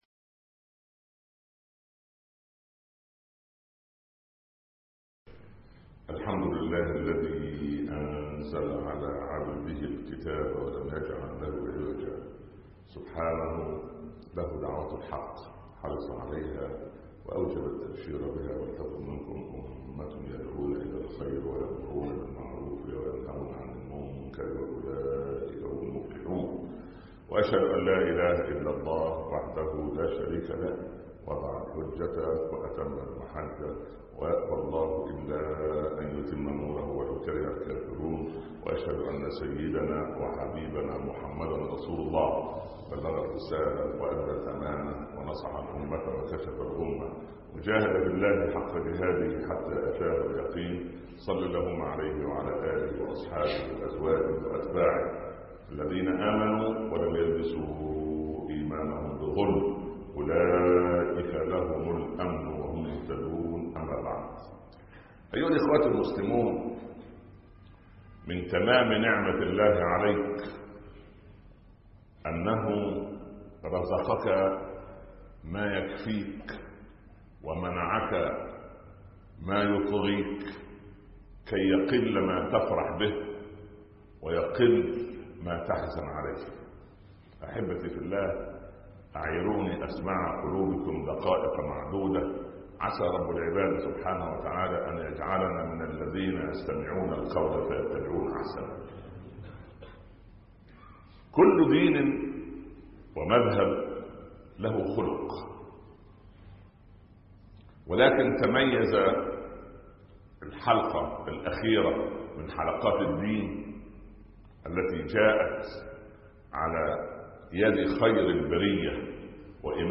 حياءٌ كله (5/12/2014) خطب الجمعة - الشيخ عمر بن عبدالكافي